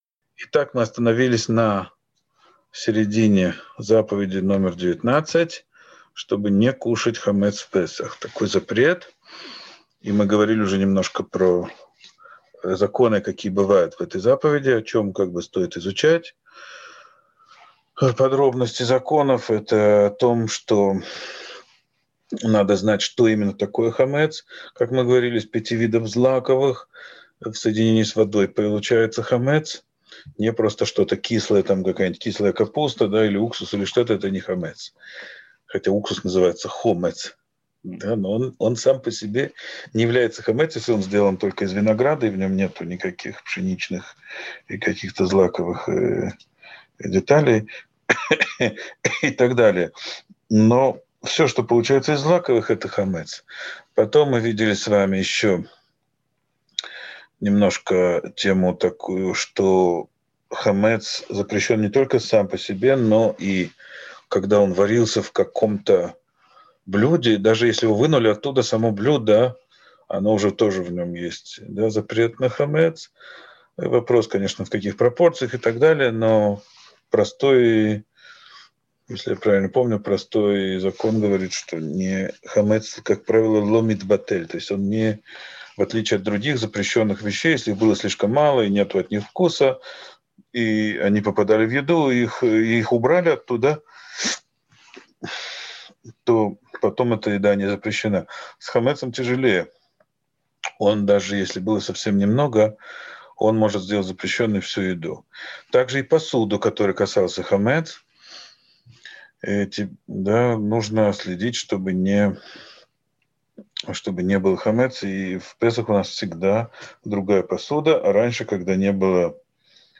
Урок 23.